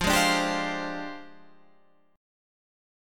F Minor 6th Add 9th